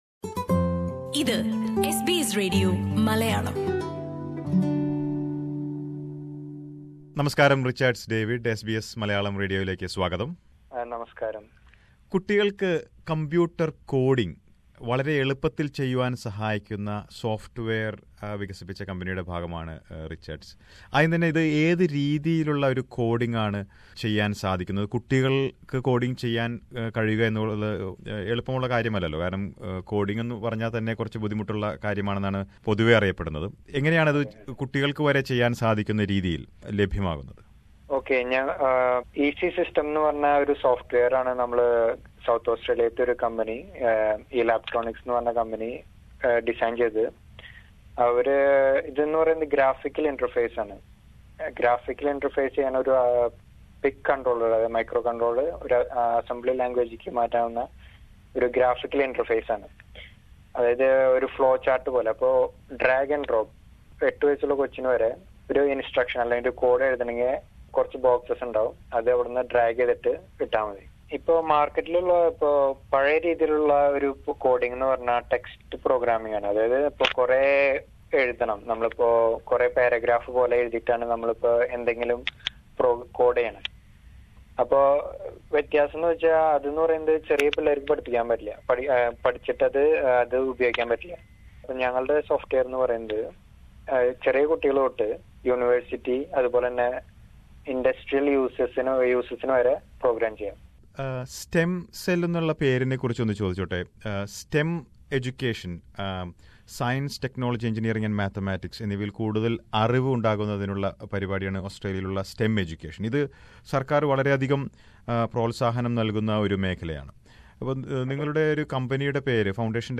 Their expertise is also helping kids in many parts of India. Listen to an interview